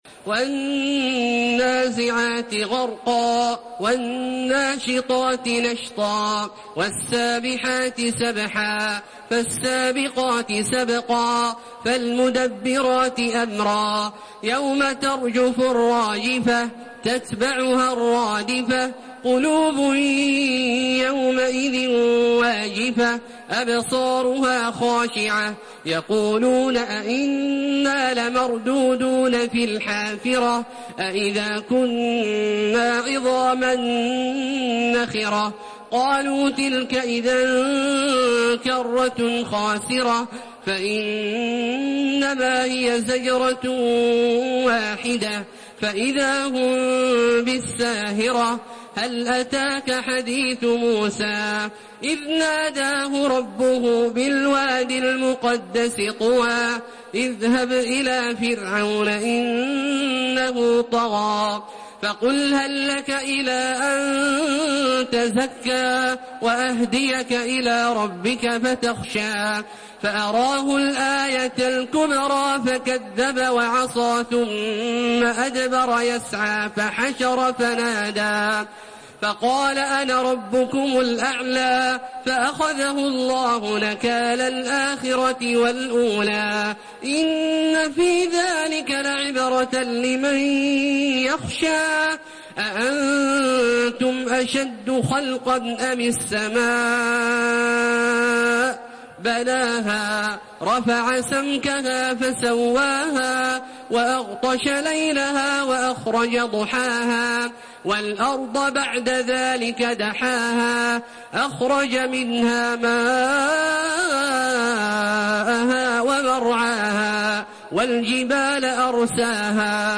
سورة النازعات MP3 بصوت تراويح الحرم المكي 1432 برواية حفص
مرتل